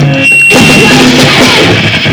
noisecore,